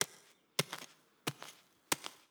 SFX_Hacke_02_Reverb.wav